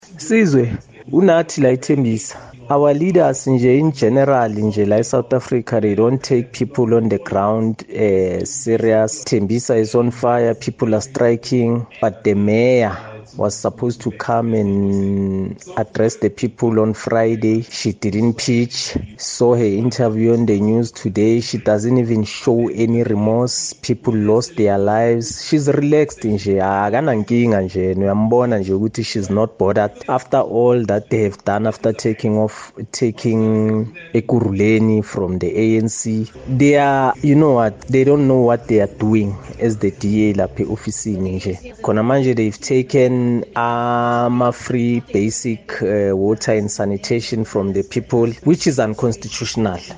However, as was evident by listener’s views on Kaya Drive, not many share the President’s sentiments.